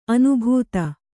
♪ anubhūta